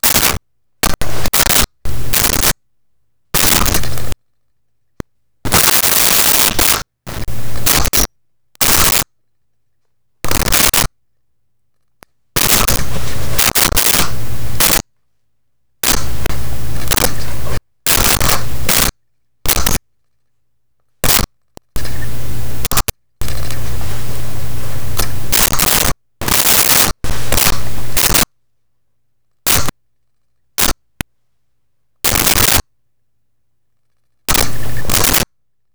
Monkey Excited Baby Chirps
Monkey Excited Baby Chirps.wav